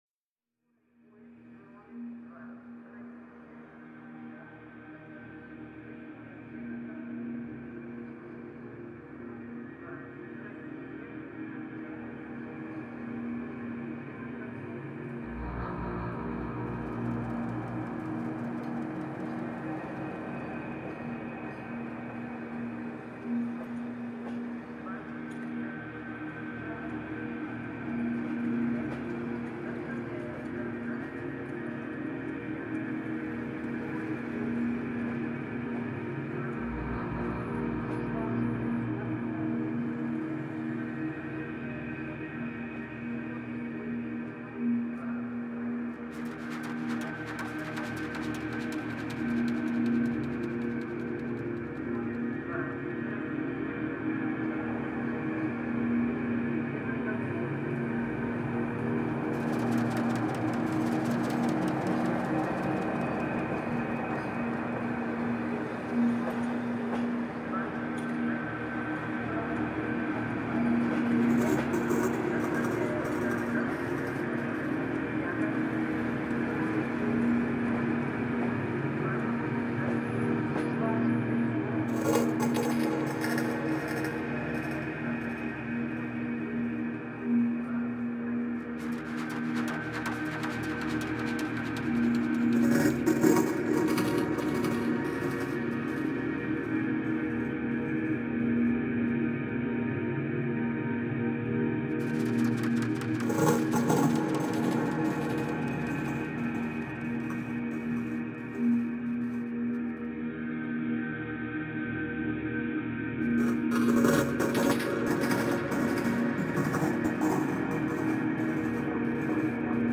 Genre: Dub Techno/Ambient/Drone/Techno.